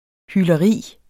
Udtale [ ˌhyːlʌˈʁiˀ ]